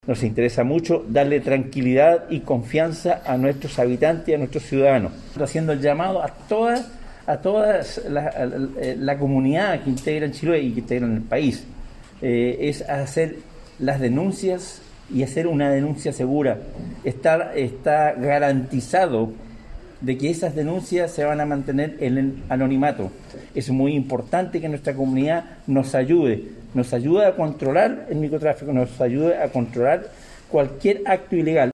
El delegado provincial Pedro Andrade destacó la acuciosa labor desarrollada por la Policía de Investigaciones para sacar de circulación esta droga en la zona.